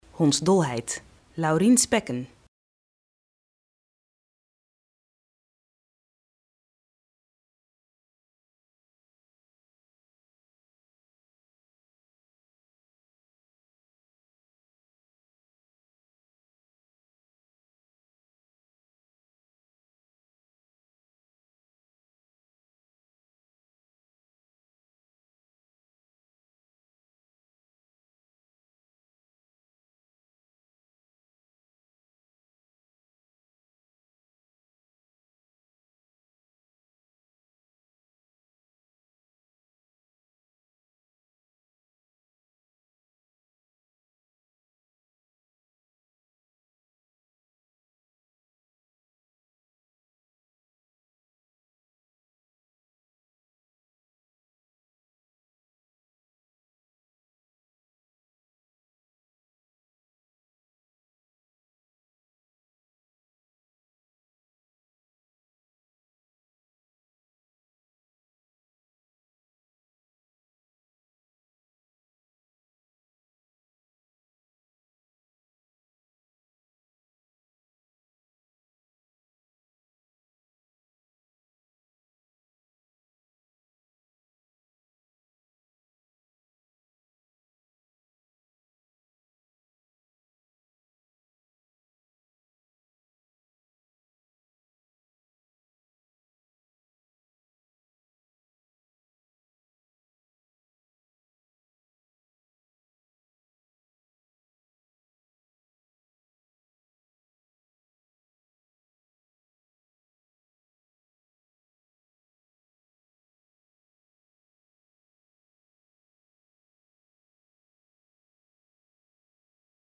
In deze video wordt u door een huisarts uitgelegd wat de hondsdolheid is, hoe u het kan voorkomen en wat u moet doen als u deze ziekte heeft.